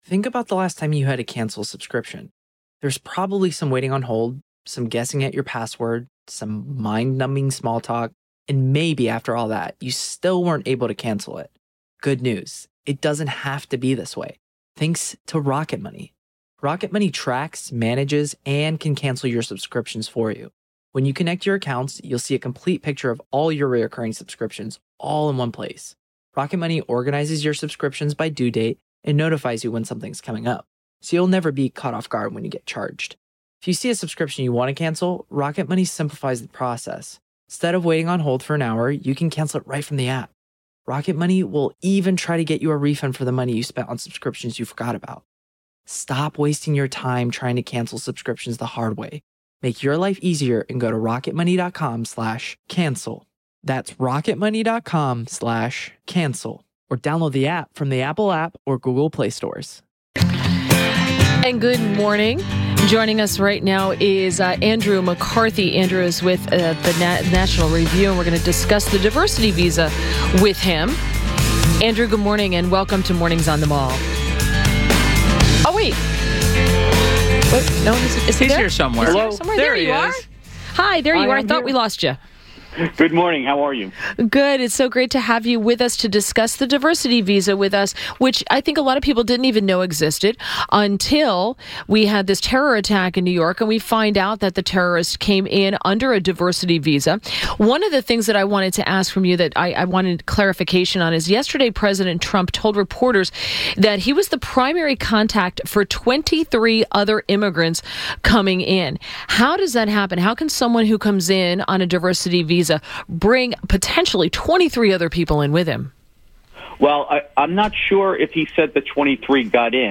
WMAL Interview - ANDREW MCCARTHY - 11.02.17
INTERVIEW – ANDREW MCCARTHY – senior fellow at National Review Institute and former Chief Assistant United States Attorney in the Southern District of New York – discussed diversity visas.